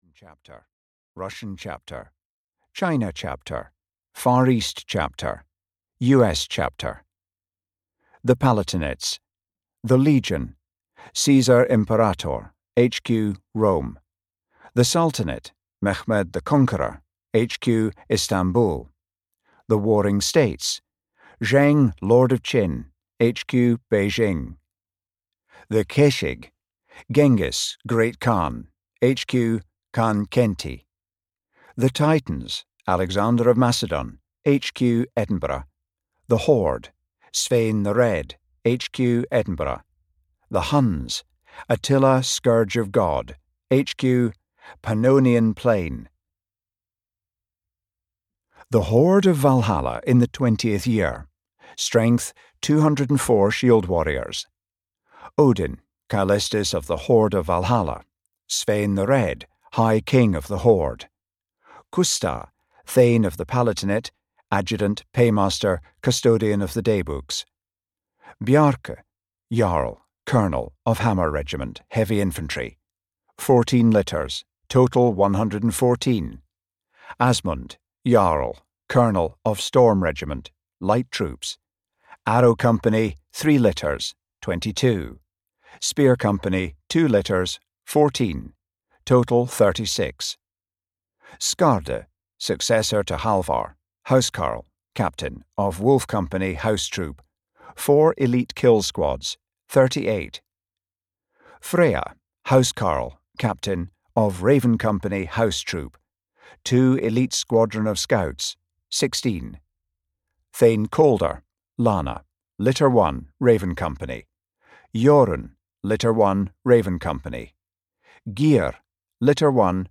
Audio knihaThe Hastening Storm (EN)
Ukázka z knihy